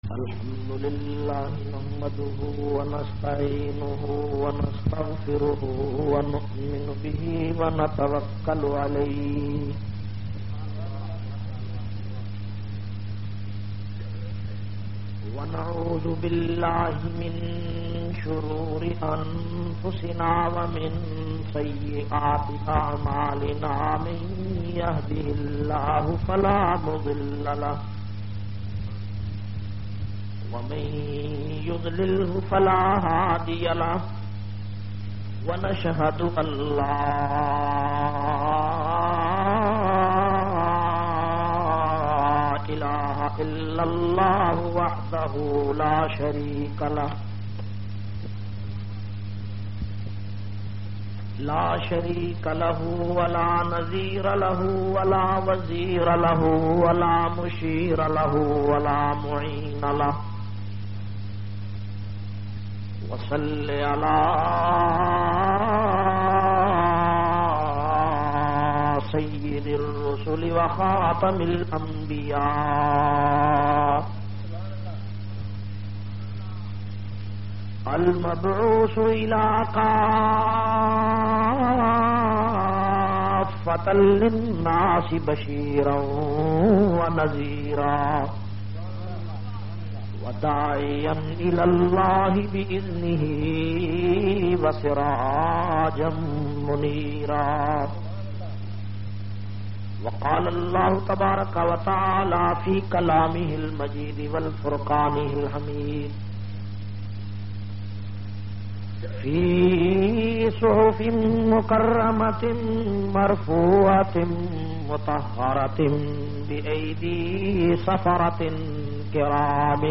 10- Ameer Muavia Kon-Madrisa Dar Ul Huda Karemia,ToheedAbad,LiaqatPur,Zila RahimYarKhan Punjabi Bayan.mp3